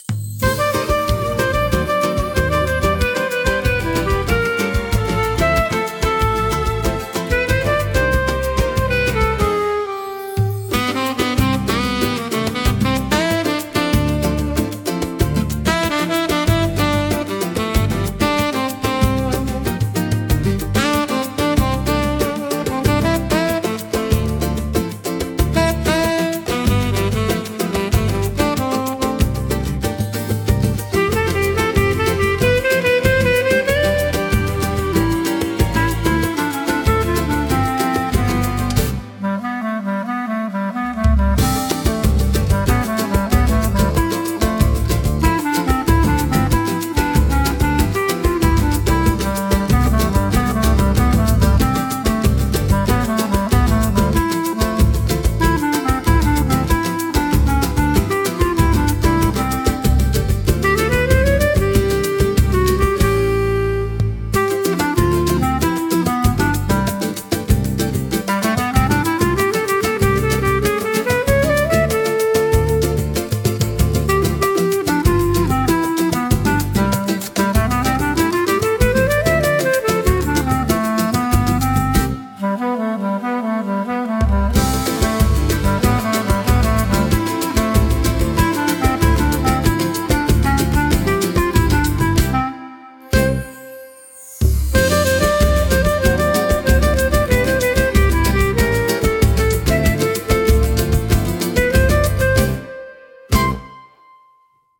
música e arranjo: IA) instrumental 8